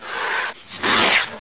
hiss.wav